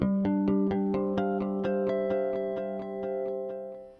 Verizon HQ Startup Sound.wav